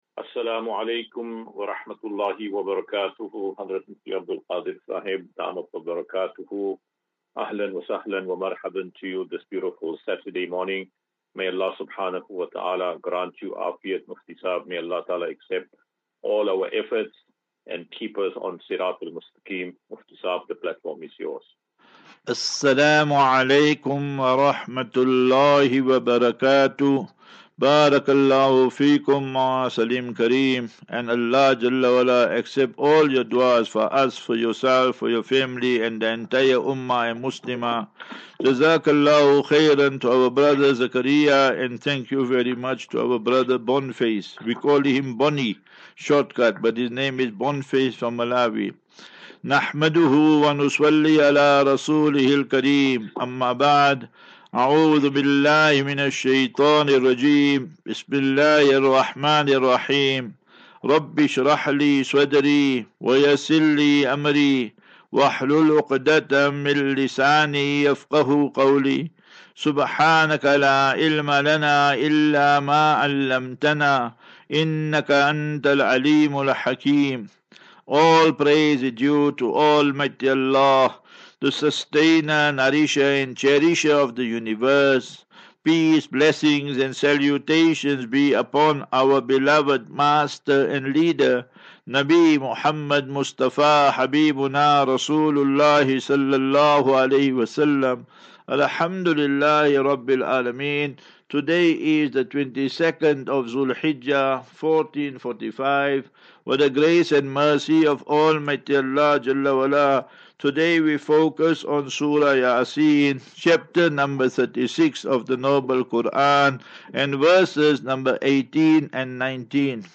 View Promo Continue Install As Safinatu Ilal Jannah Naseeha and Q and A 29 Jun 29 June 2024.